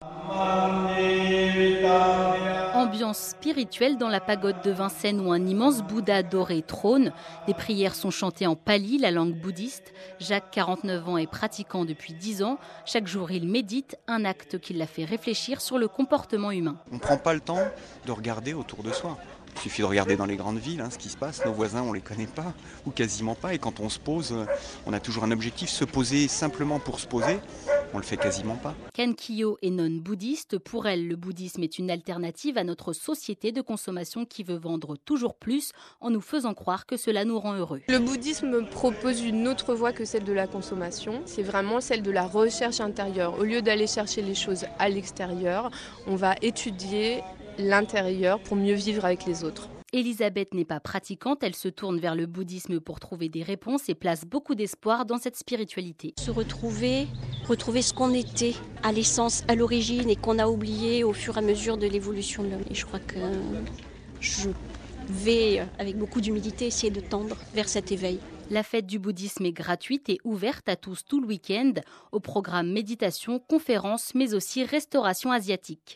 Téléchargement: Reportage sur RTL (MP3, 1.2 Mio)